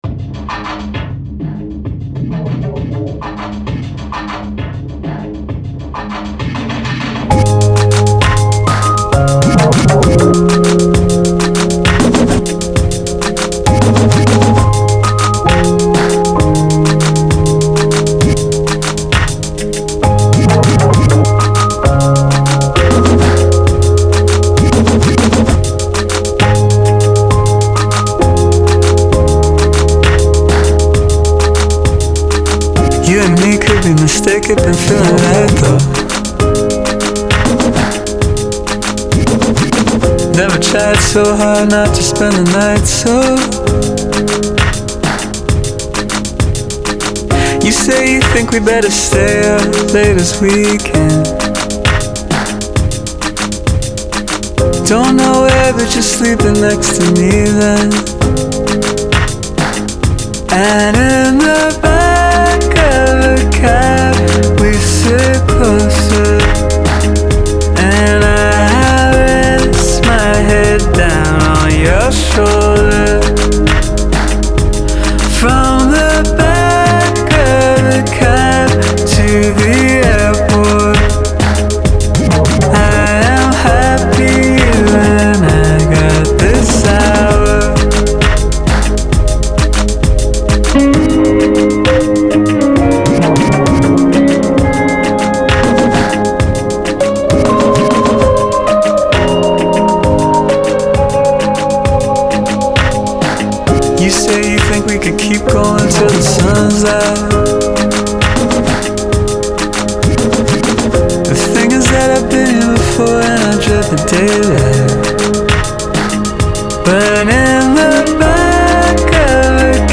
Genre : Alternative, Pop